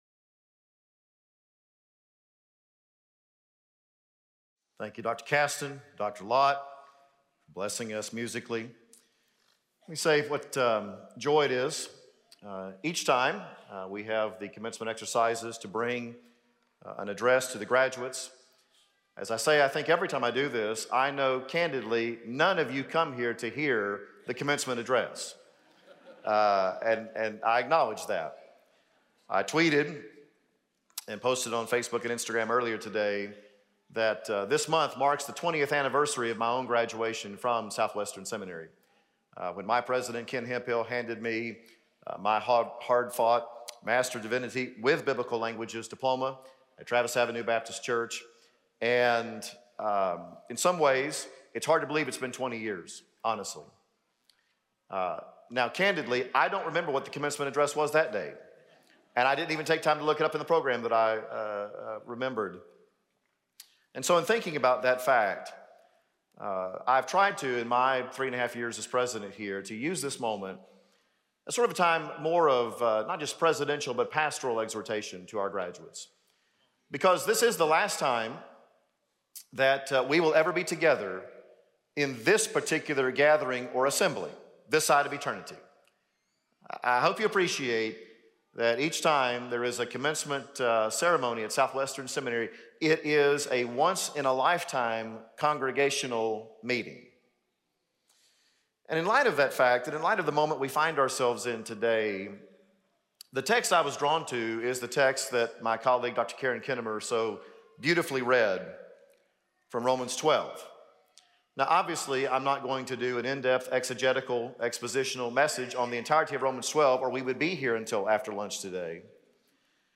Spring 2022 Commencement Address